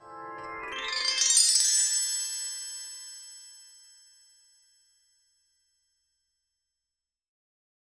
magical-sparkles-croya3kw.wav